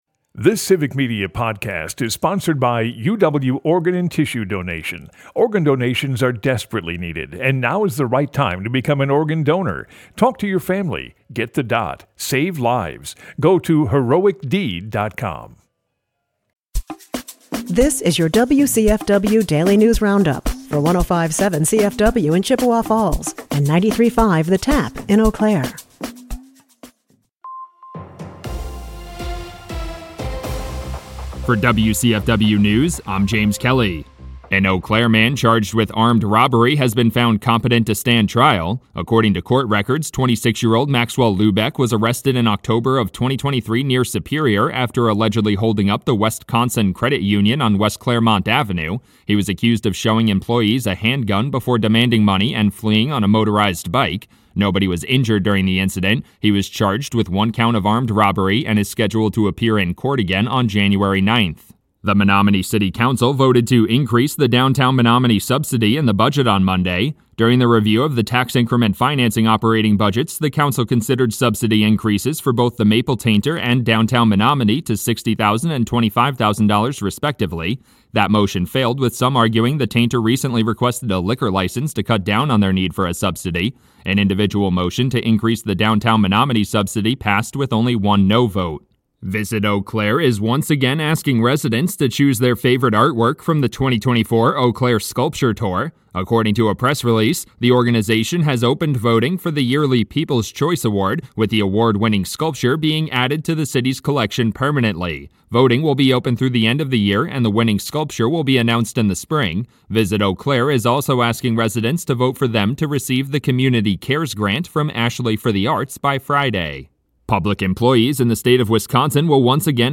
wcfw news